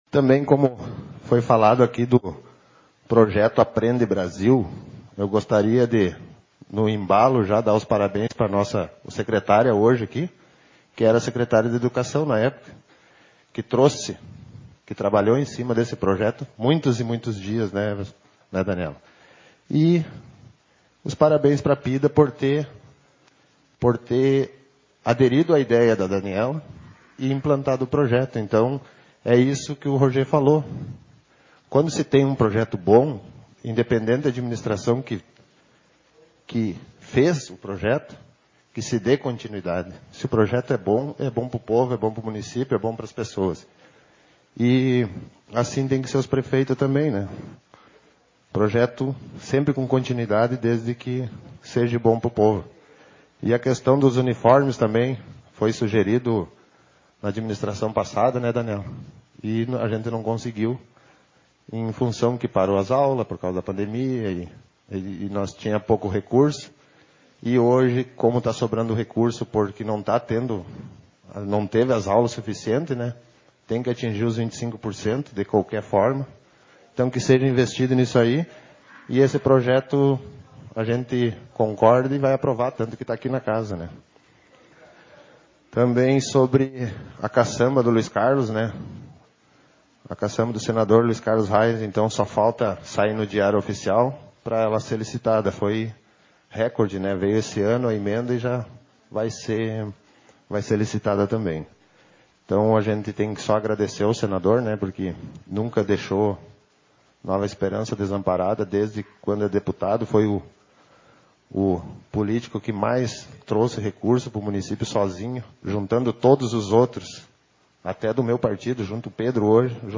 Sessão Ordinária 39/2021